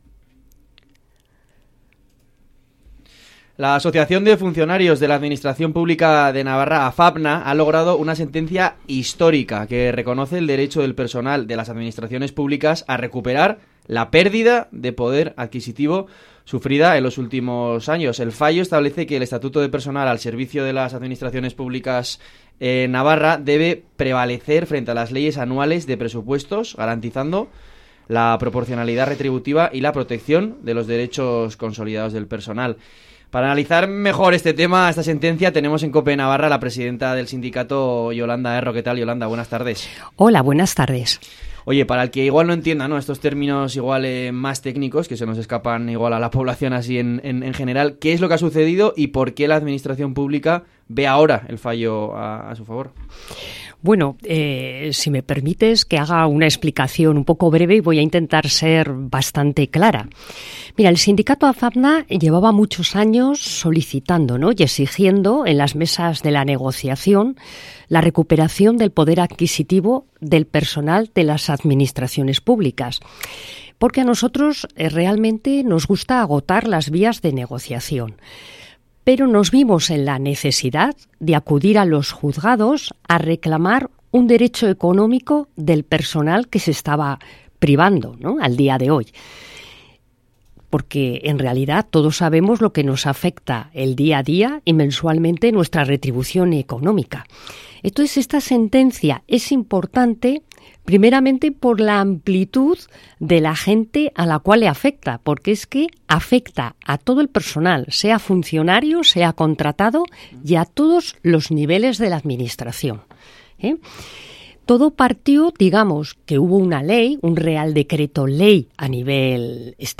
Desde el sindicato os acercamos el corte de la COPE que tuvo lugar ayer 28 de  octubre de 2025, sobre la sentencia histórica de AFAPNA sobre la reversión del poder adquisitivo para el personal de la Administración Pública: